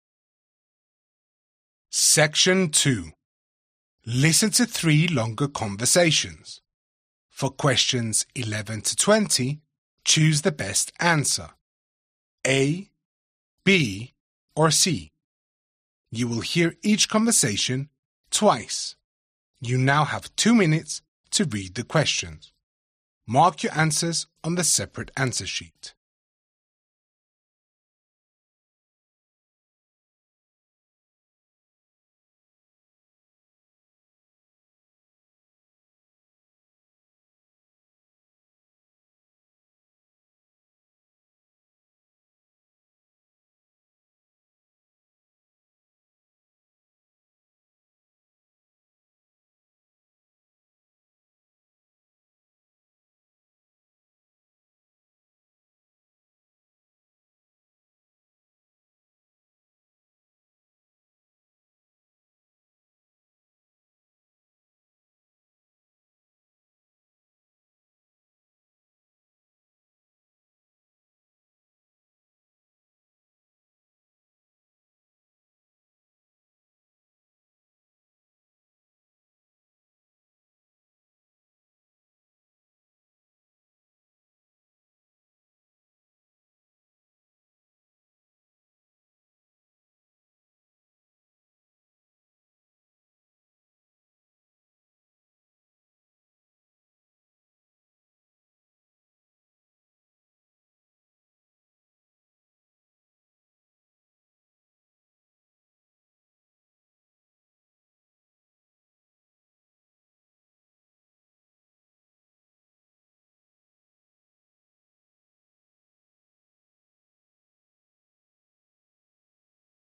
You will hear each conversation TWICE.